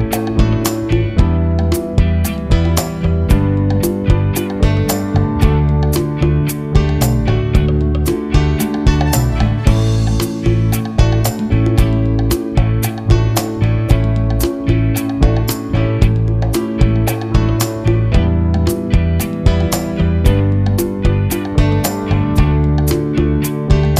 No Saxophone Pop (1970s) 4:30 Buy £1.50